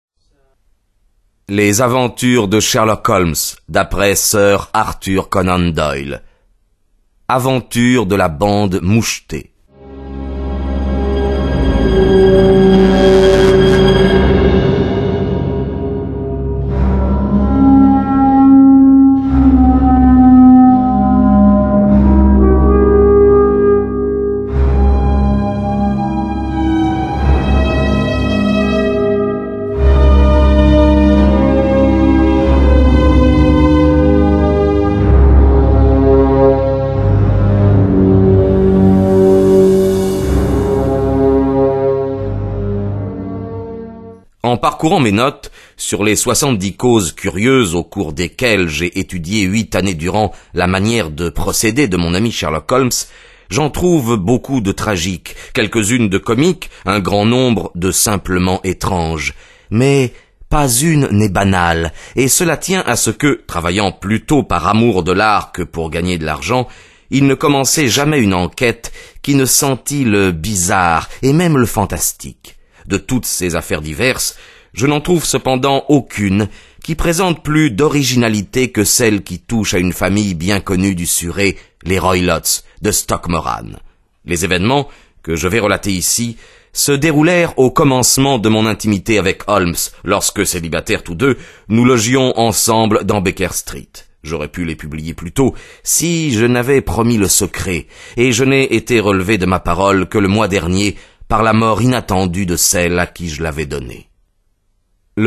Click for an excerpt - Sherlock Holmes - L'aventure de la bande mouchetée de Arthur Conan Doyle